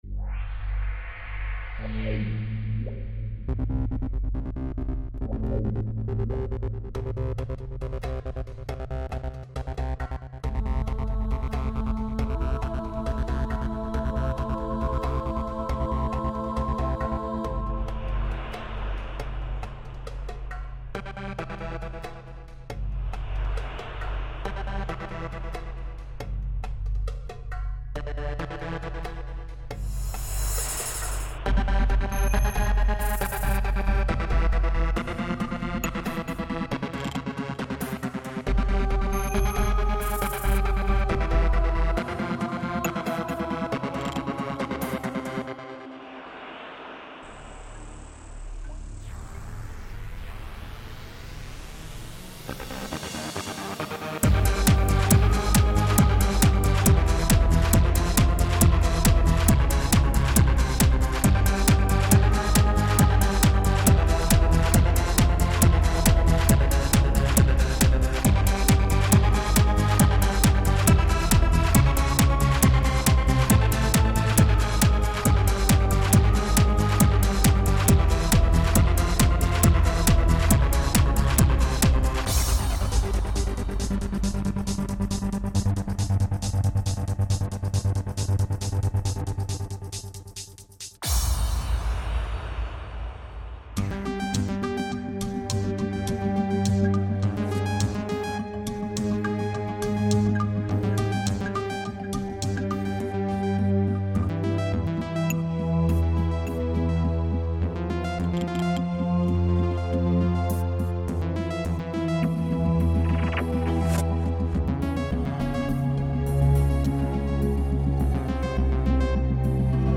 Mix impressionnant et ambiance électronico-gothico-planante
J'adore comment la voix accompagne les accords, ça évoque une sorte de transe, un dopage à l'adrénaline...